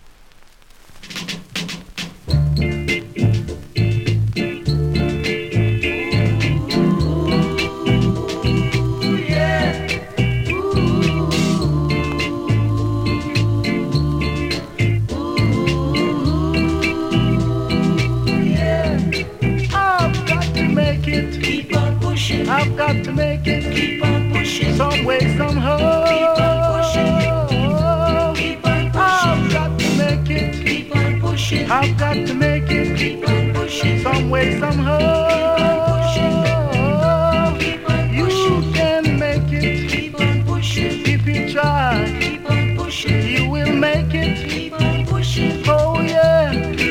REGGAE 70'S
両面そこそこキズあり、多少ノイズもありますがプレイは問題無いレベル。